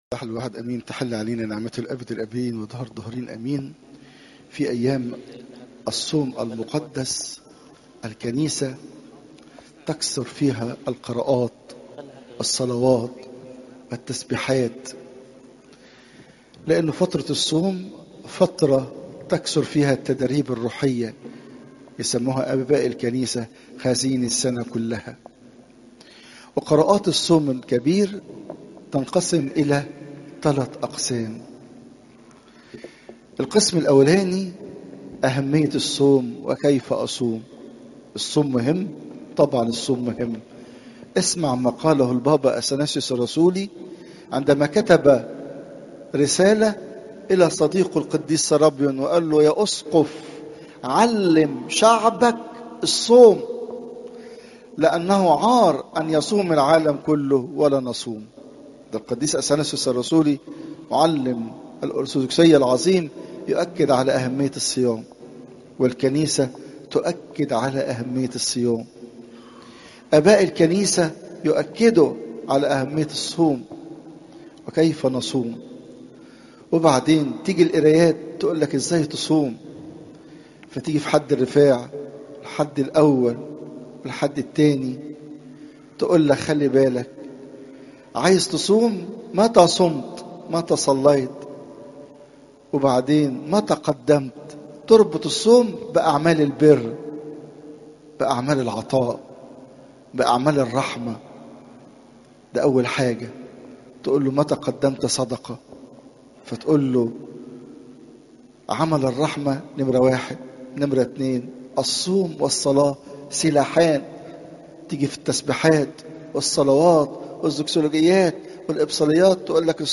Popup Player تحميل الصوت تحميل الفيديو الانبا مكارى الأحد، 16 مارس 2025 15:16 عظات قداسات الكنيسة الصوم الكبير احد الابن الضال (لو 15 : 11 -32) الاسبوع الثالث الزيارات: 217